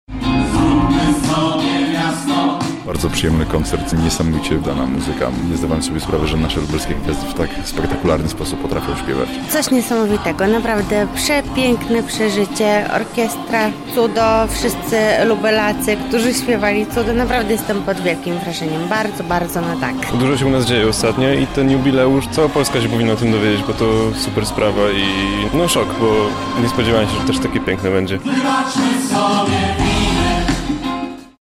W sobotę w sali operowej Centrum Spotkania Kultur widzowie wysłuchali kantaty skomponowanej specjalnie na okazję rocznicy przyznania praw miejskich.
Oprawę muzyczną jazzrockowej kantaty przygotował muzyk i kompozytor Tomasz Momot.
W tworzeniu widowiska wzięło udział prawie 150 artystów.